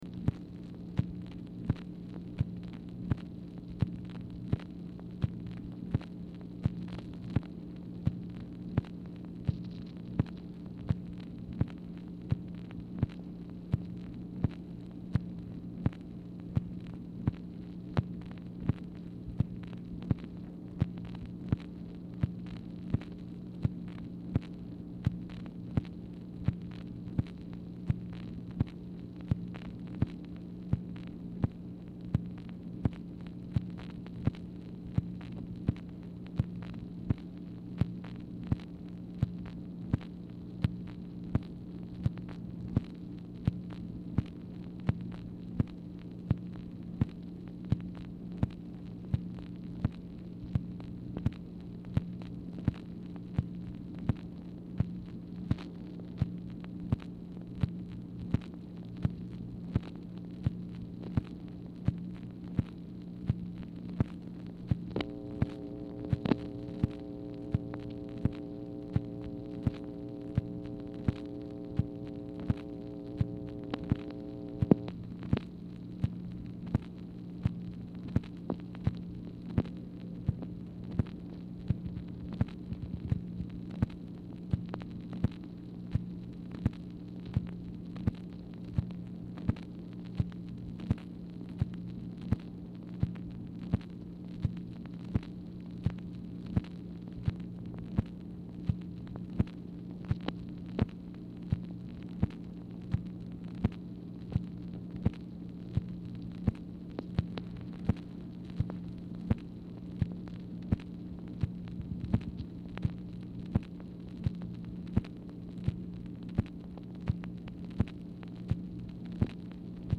Telephone conversation # 8827, sound recording, MACHINE NOISE, 9/3/1965, time unknown | Discover LBJ
Format Dictation belt
Specific Item Type Telephone conversation